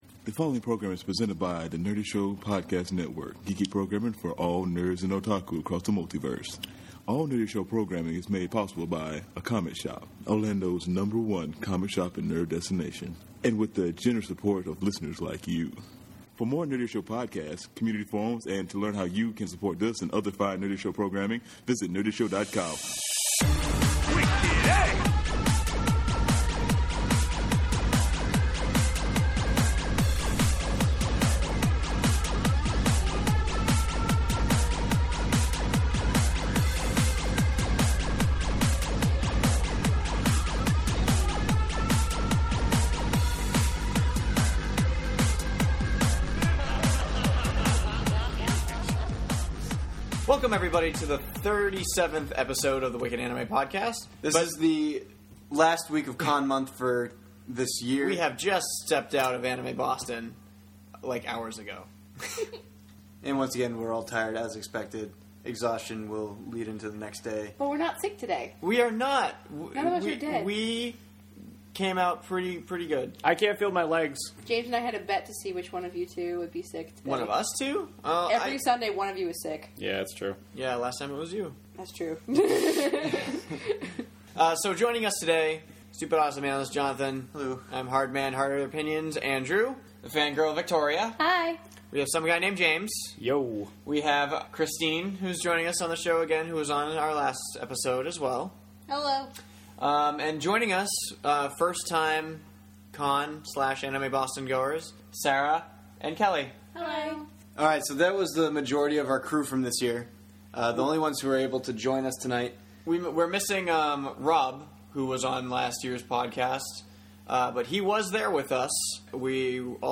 This episode contains two separate recording sessions; one where we’re too tired for rational thought and one where members got sick!